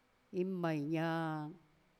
次に、同じ単語を今度はセンテンスの中の環境で聞いてみてください。
また、今回の我々のデータは、宮古語池間方言を話す一部の地域の話者（西原地区の話者）、しかも７０歳以上の話者のデータしか扱っていないことも特記に値するでしょう。
dog_005_KA_sentence.wav